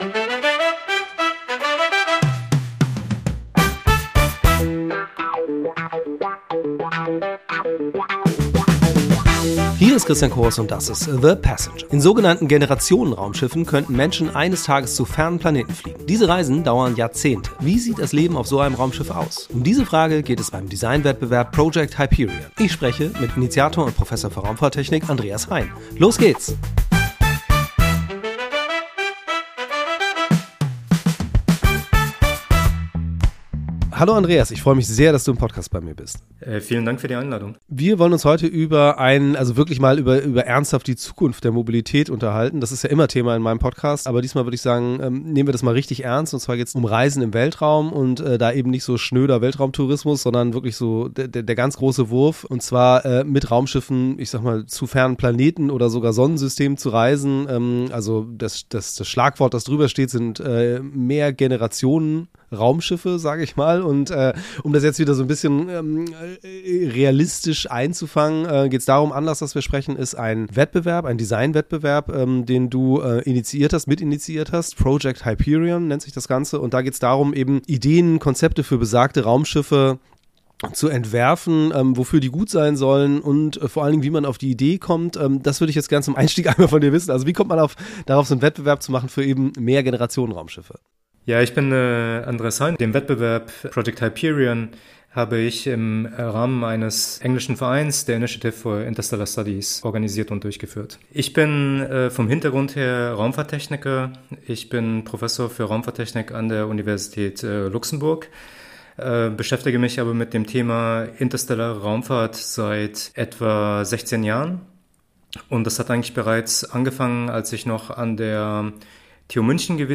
Doch im Gespräch wird es dann schnell konkret: Was würde so ein Raumschiff kosten? Wie sorgt man dafür, dass die Reisenden über Jahrzehnte auf beschränktem Raum nicht nur überleben, sondern eine gute Zeit haben?